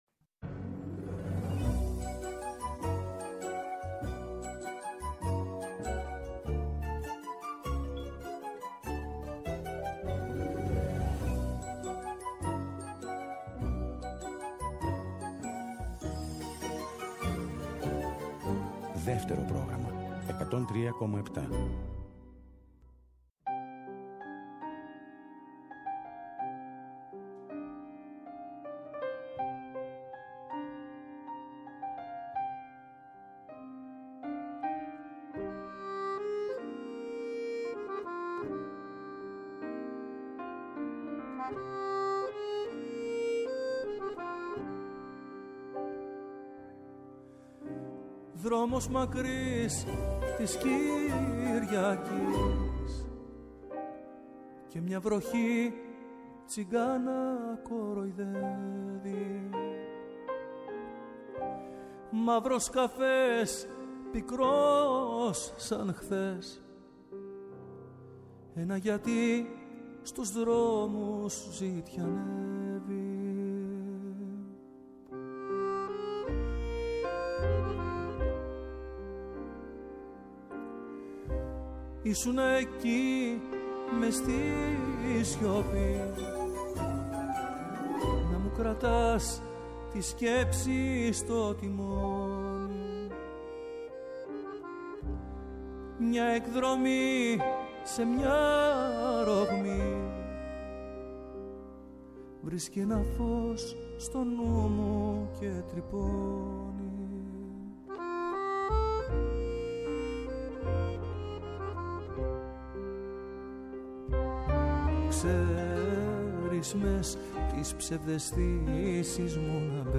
Εχει η Ζωη Γυρισματα Συνεντεύξεις